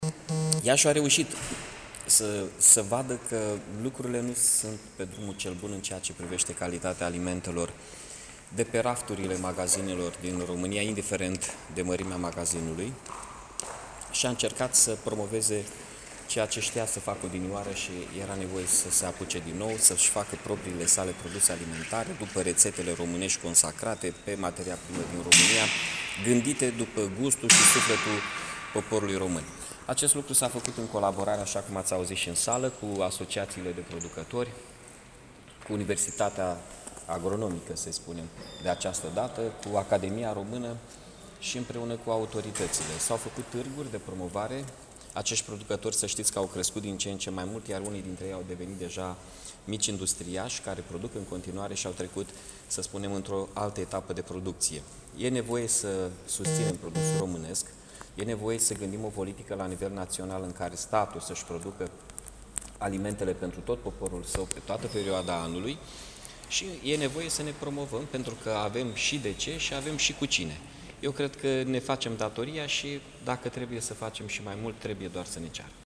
Primarul municipiului Iași, Mihai Chirica: ” Producătorii mici se transfirmă în industriași „